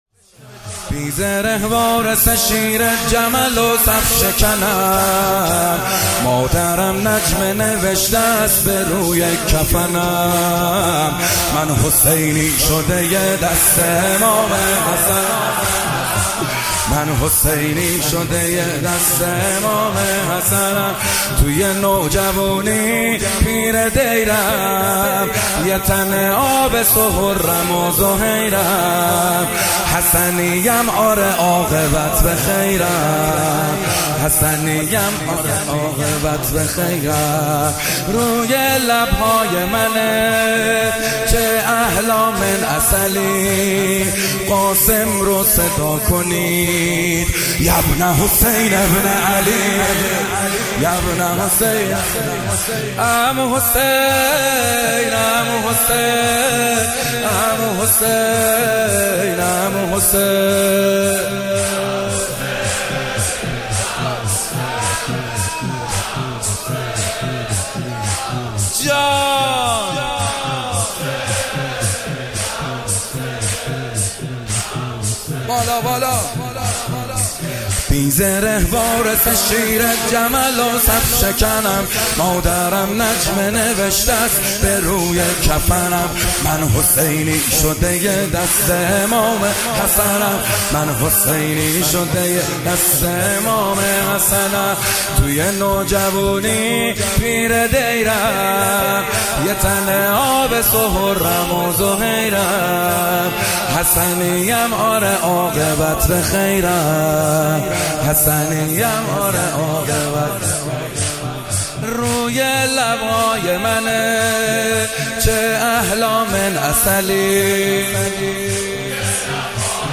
مداحی جدید کربلایی محمدحسین حدادیان شب ششم محرم 97 مهدیه امام حسین